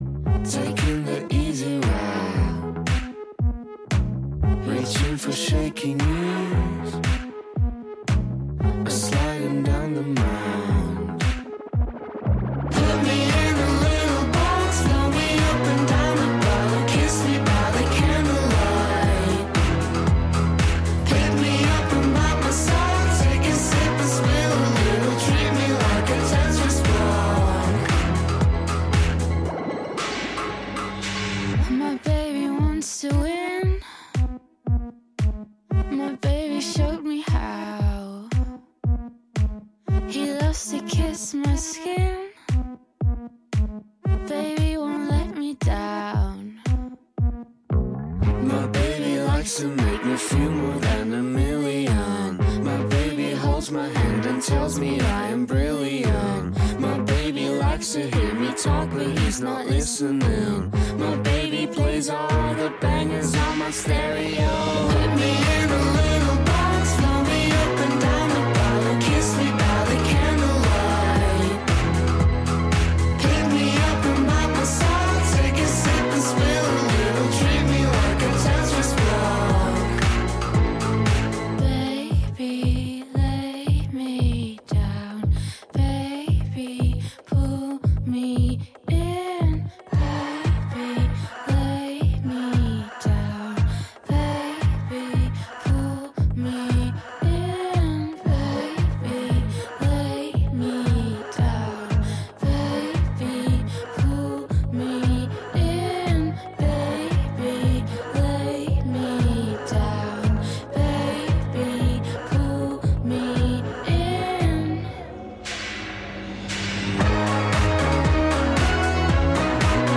Two amazing friends come on the show to discuss music and have a wonderful time.